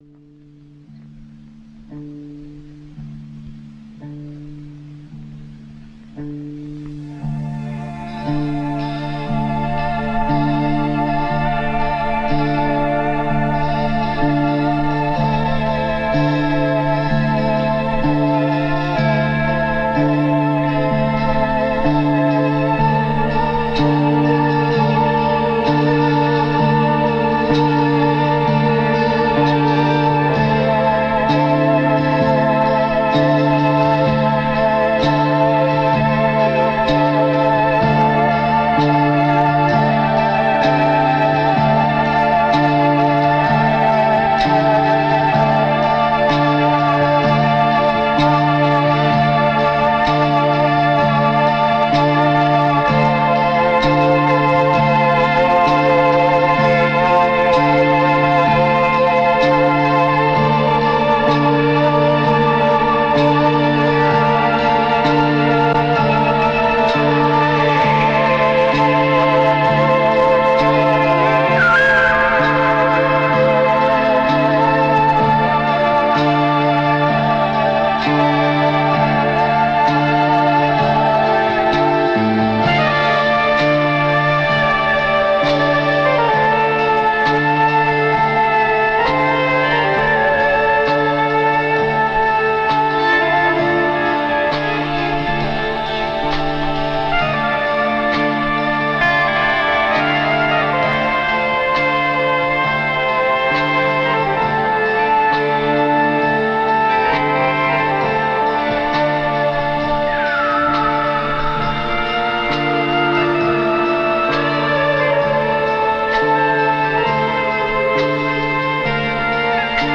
Progressive Rock, Psychedelic Rock